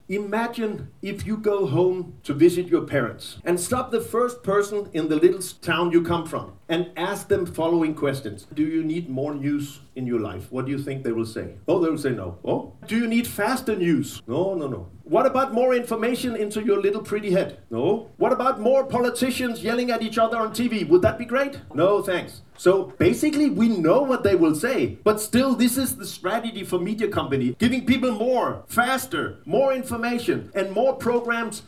O svemu tome razgovaralo na konferenciji koja je okupila novinare, urednike, medijske stručnjake, ali i studente.